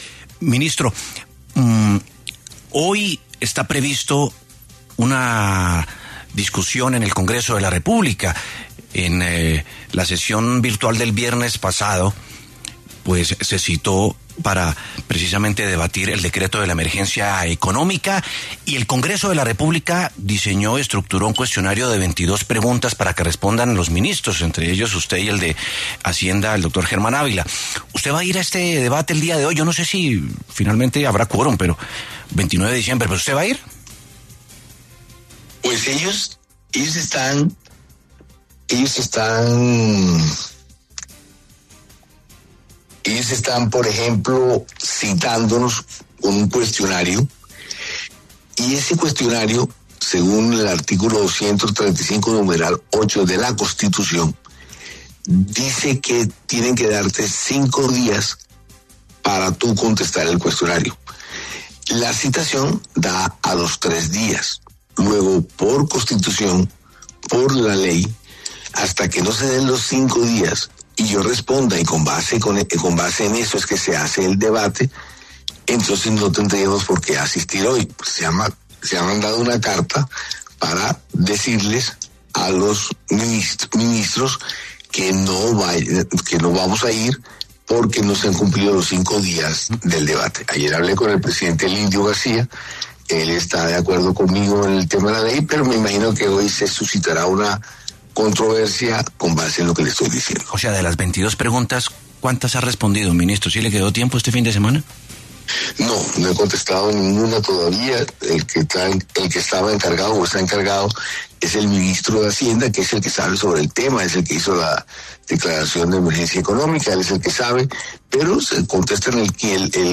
El ministro del Interior, Armando Benedetti, confirmó en los micrófonos de La W que los ministros del Gobierno del presidente Gustavo Petro no asistirán al debate de control político citado para este 29 de diciembre sobre el decreto de emergencia económica.